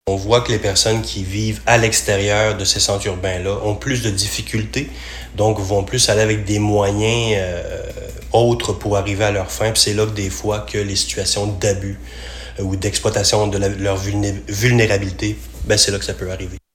En entrevue avec la radio CIEU-FM, il souligne que le problème est surtout criant le soir et la fin de semaine en raison du manque de services.